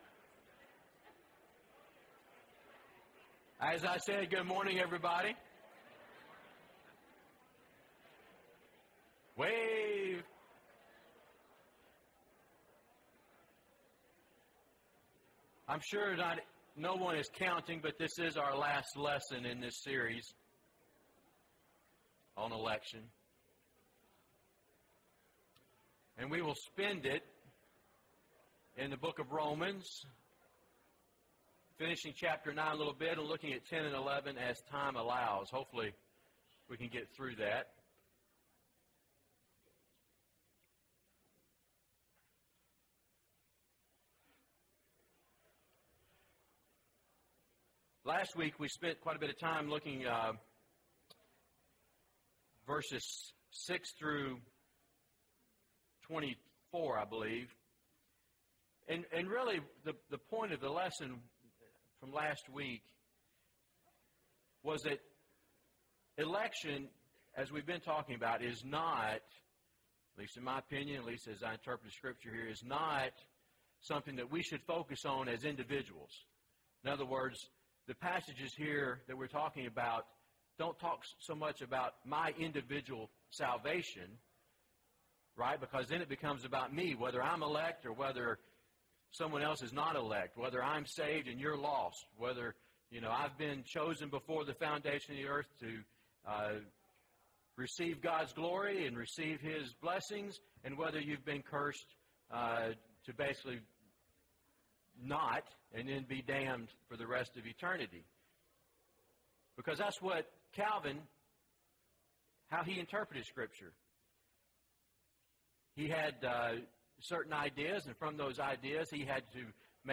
The Righteous Live By Faith: Everyone (13 of 13) – Bible Lesson Recording